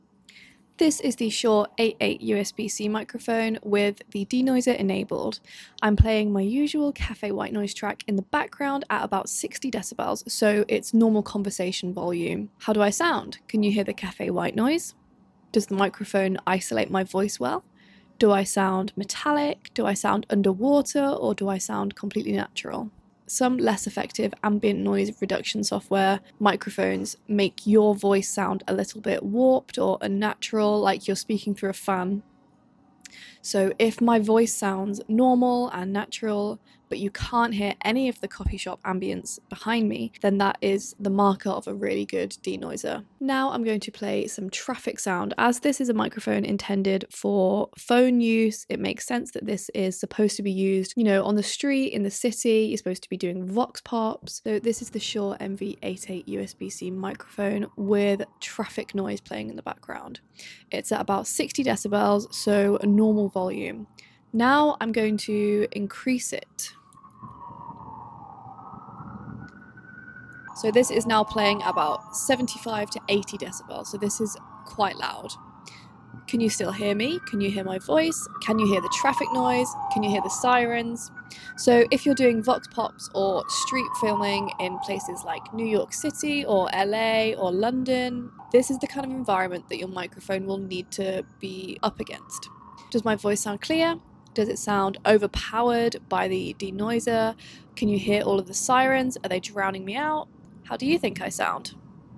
As you can hear, my voice is isolated well. Obviously, there’s a touch of harshness to my voice, but this is a given when using really strong ambient noise reduction. The mic did a fantastic job of eradicating the loud sirens and cars in the second half of the track, and pretty much obliterated all café noise in the first half of the track.
denoiser ON.mp3